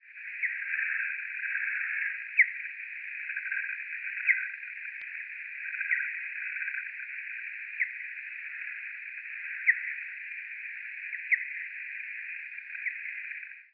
V létě jsem se po večerech toulal se speciálně upraveným magnetofonem a natáčel.
Všechny zde uvedené zvukové ukázky byly zpomaleny v poměru 1:20.
výkřiku (délka 55kB) pravděpodobně netopýra vodního vidíme tón o délce 1,9 ms, který nabíhá a doznívá tak pomalu, že by určení směru podle časového rozdílu nebylo možné.